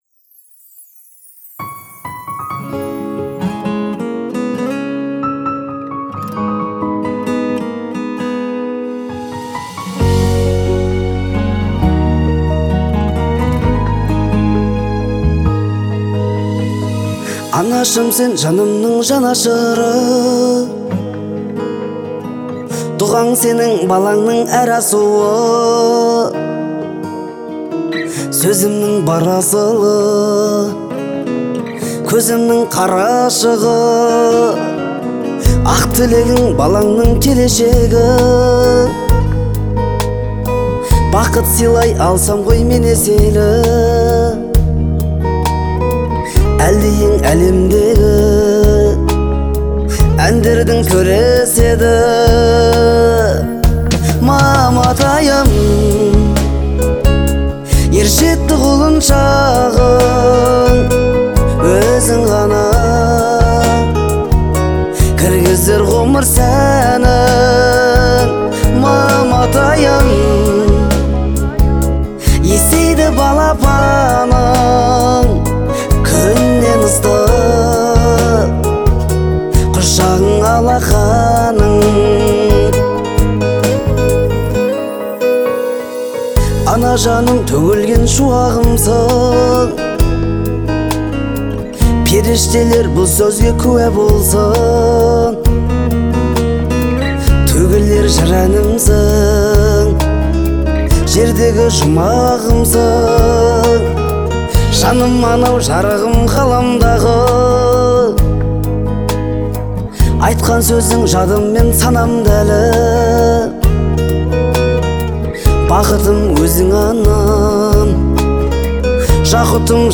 • Категория: Казахские песни /